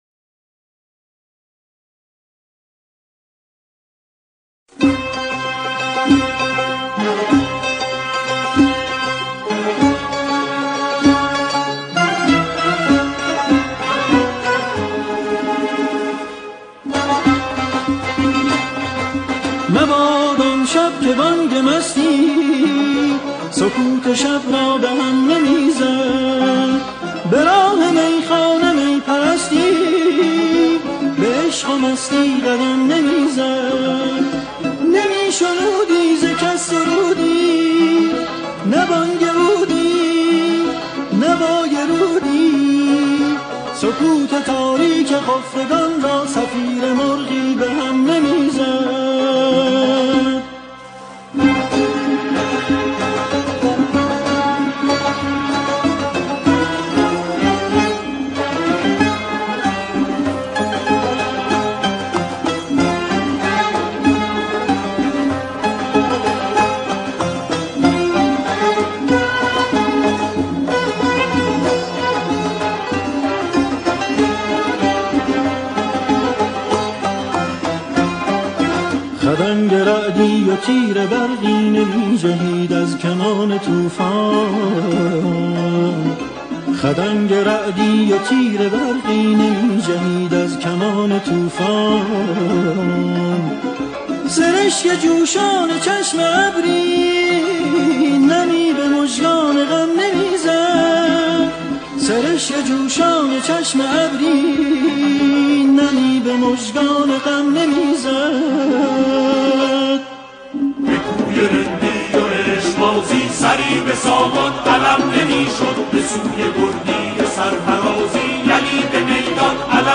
سرودهای دهه فجر